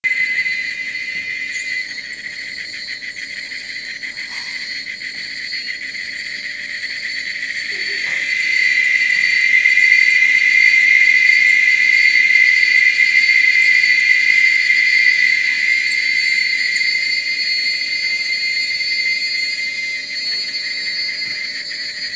Cigale
D’ailleurs, c’est la plus grande RAISON de ma FASCINATION : leurs chants très différents et oh! SI FORTS!!!!
Je partage avec vous, pour que vous puissiez aussi avoir accès à ce phénomène exceptionnel, 2 extraits sonores de ce que nous entendons toute la journée!
cigales-las-cruces.wav